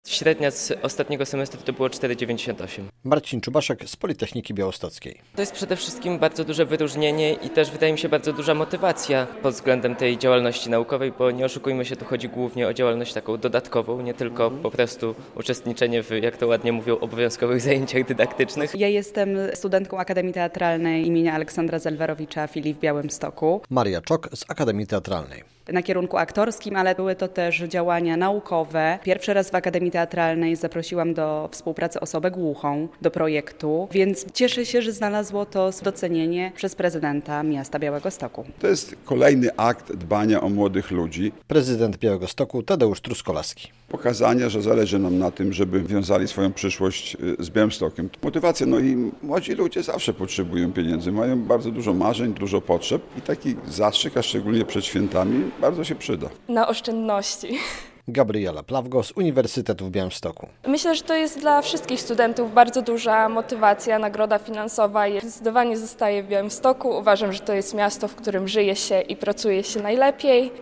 Stypendia dla najlepszych studentów - relacja